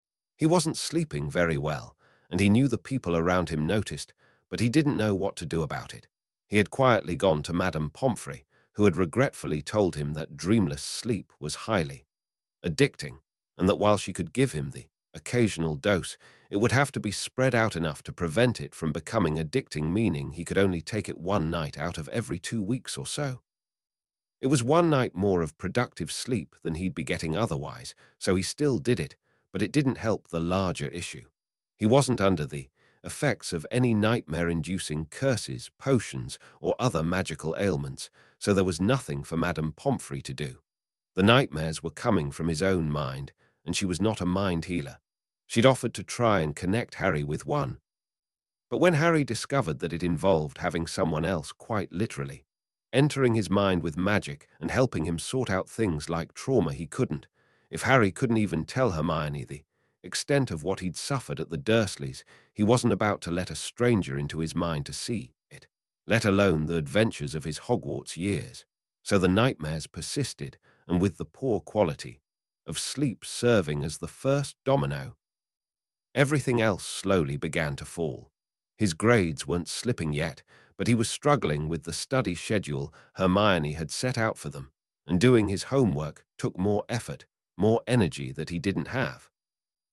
Here's a UK English sample of the same text. It sounds fine to my ear, but I'm not British.